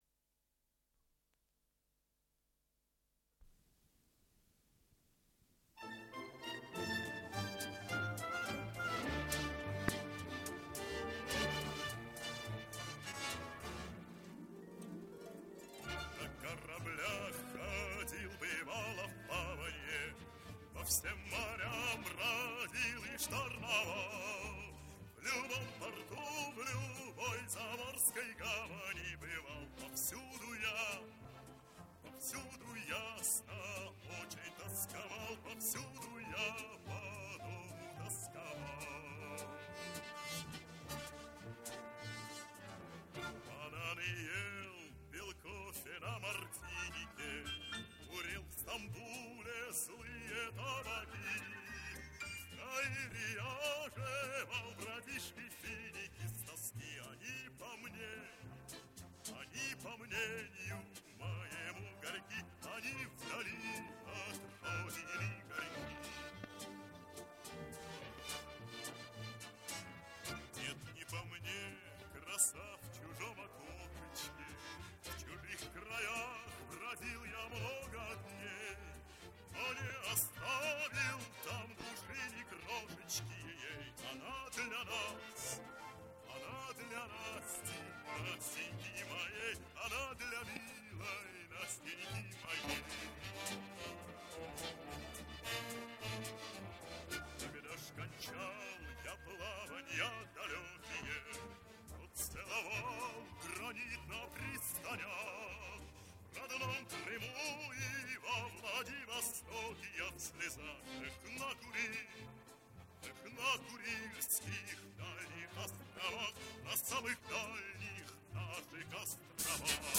Дубль моно